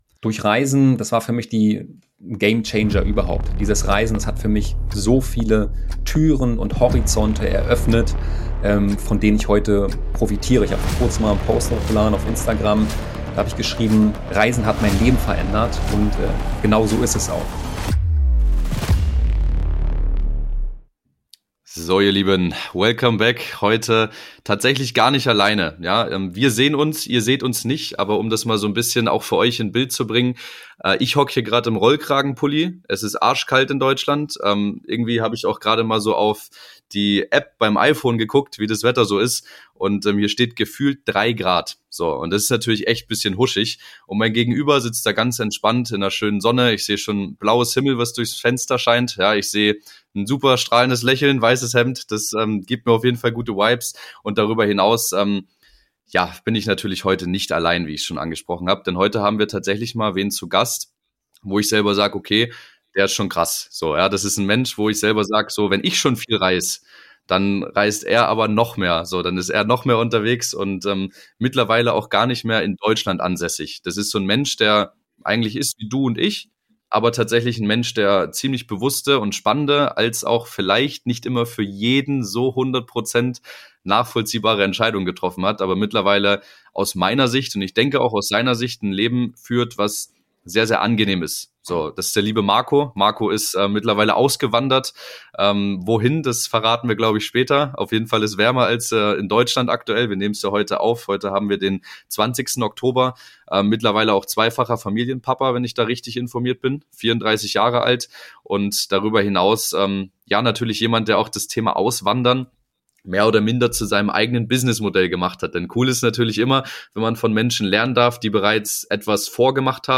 Interview - Folge 26 ~ MNDFLX Podcast